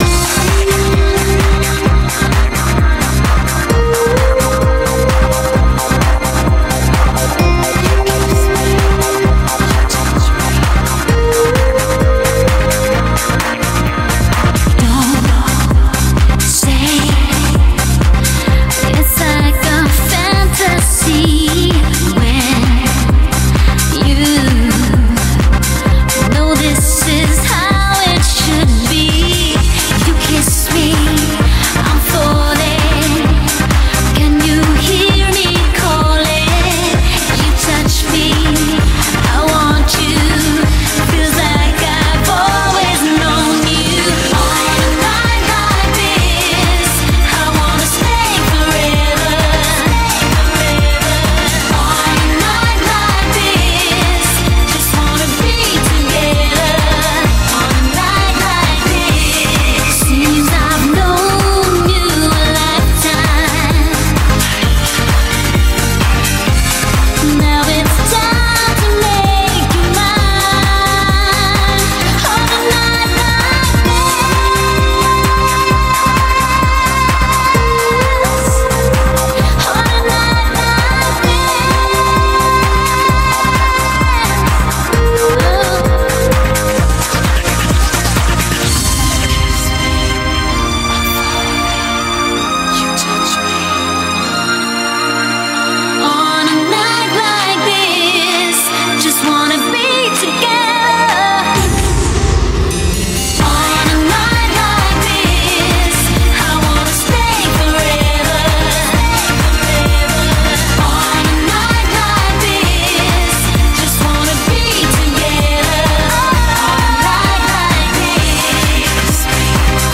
BPM130
Audio QualityMusic Cut